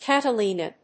/ˌkætʌˈlinʌ(米国英語), ˌkætʌˈli:nʌ(英国英語)/